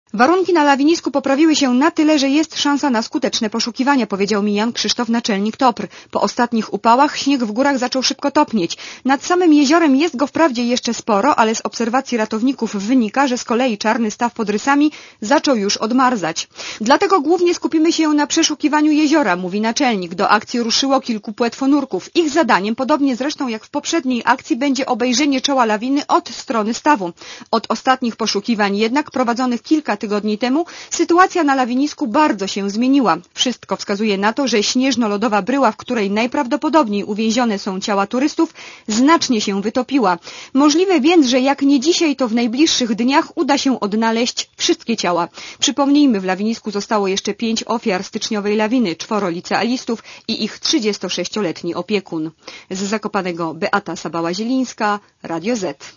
Źródło: Archiwum (RadioZet) Źródło: (RadioZet) Komentarz audio (220Kb) Do czasu odnalezienia ciał szlak z Morskiego Oka na Czarny Staw pod Rysami i na Rysy jest zamknięty.